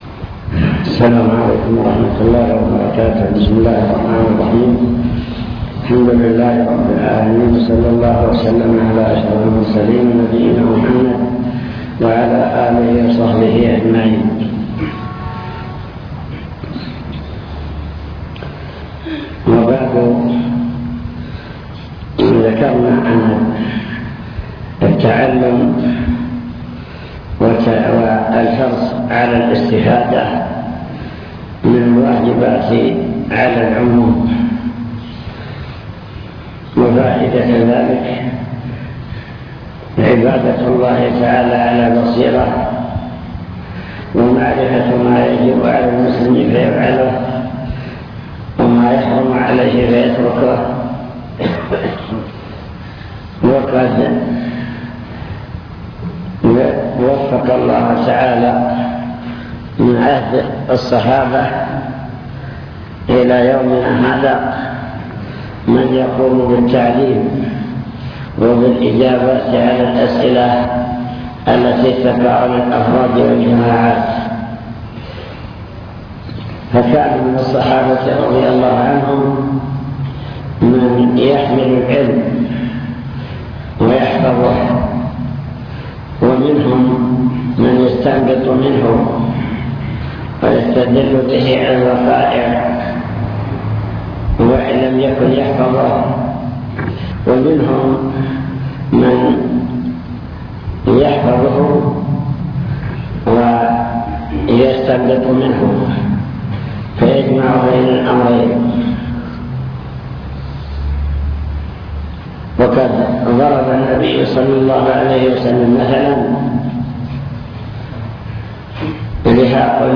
المكتبة الصوتية  تسجيلات - لقاءات  كلمة مع فتاوى وأجوبة السائلين